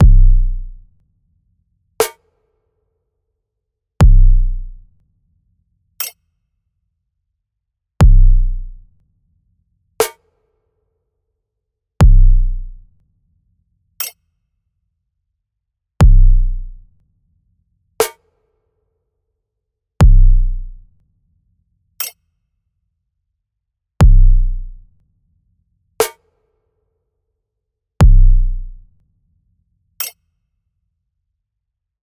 b. Algebraic Beats Audio:A 30-second factoring drumbeat with a material journal reflection, capturing one’s rhythmic listening.
Kick Drum Finding GCF Hit on the 1st and 3rd beats;
Set to strong velocity to represent this foundational, decisive first move in factoring that requires clarity.
Snare Drum Trying to split the middle term Fire on the 2nd and 4th beats.
Use medium velocity to correspond to splitting the middle term, while the 4th beat snare is softer to mimic verifying the split result- a quick check to ensure accuracy.
Rhythmic Cycle Repetitive practice Repeat for 30 seconds, aligning with the repetitive, step-by-step nature of factoring practice.
Factorization-beat.mp3